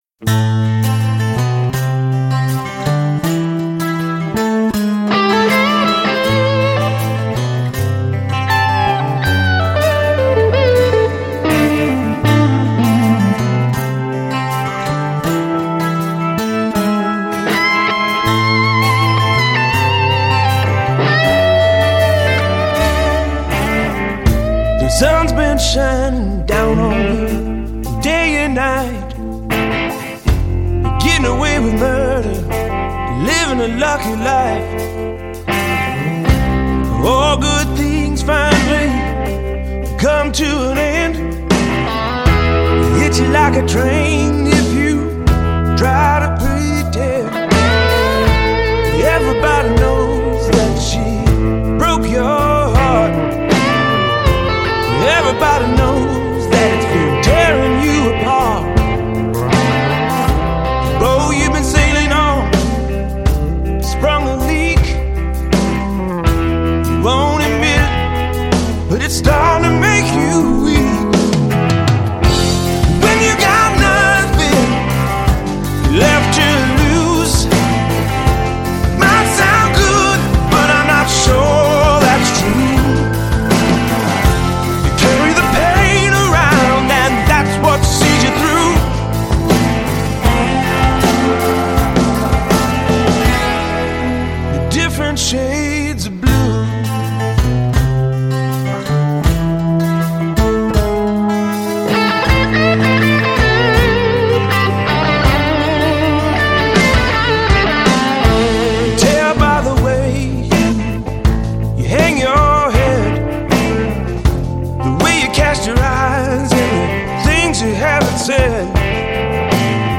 Жанр: Blues